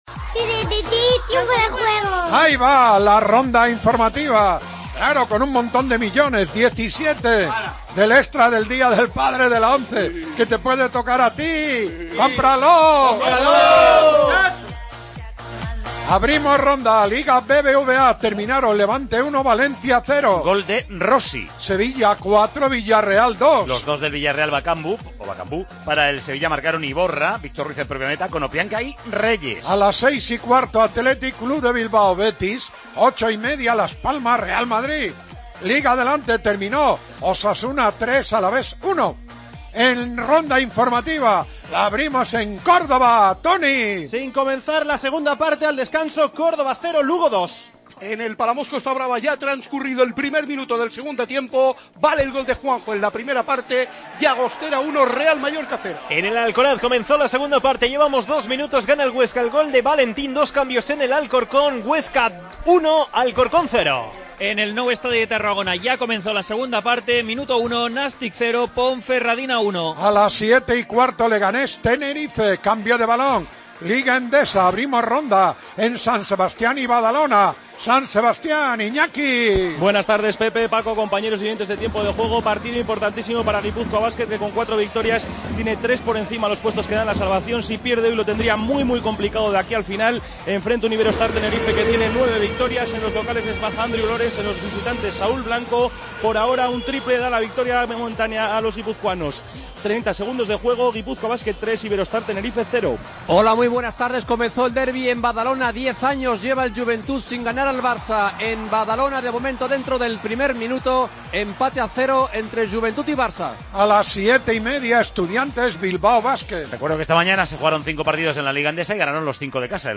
AUDIO: Escucha la primera parte del Athletic-Betis, partido de la 29ª jornada de la Liga BBVA y todo el deporte en directo.